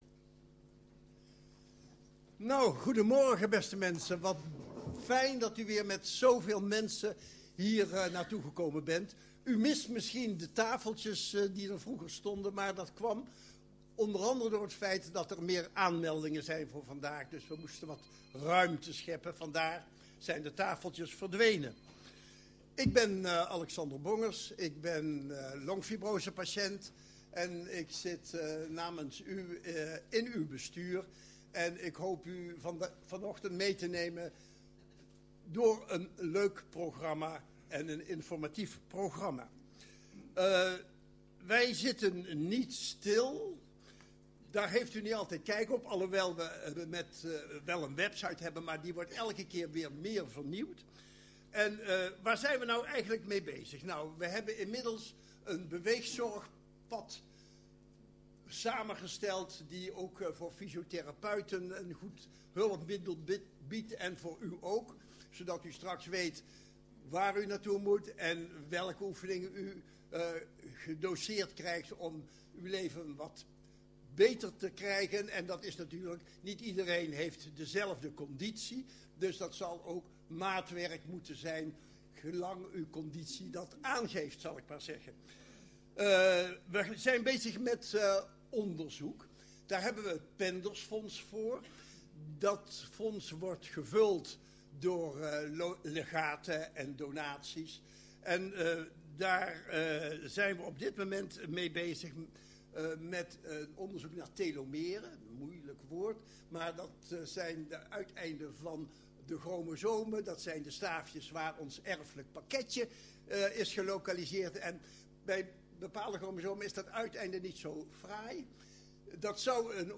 Tijdens de presentaties werd geboeid geluisterd: de sprekers deelden waardevolle kennis en praktische tips die voor veel aanwezigen direct toepasbaar zijn in het dagelijks leven.
Opname ledendag 2025.mp3